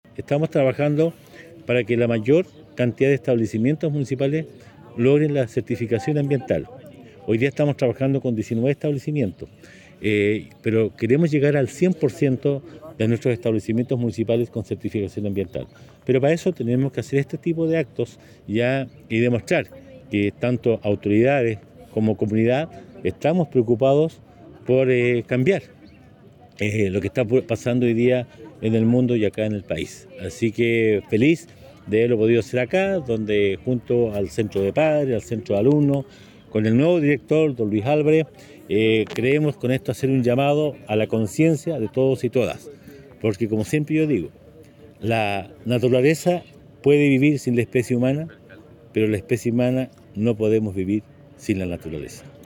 Del mismo modo el Alcalde Carrillo señaló que se continuará trabajando arduamente con los establecimientos bajo administración municipal, para que en el futuro todos puedan contar con la certificación medioambiental.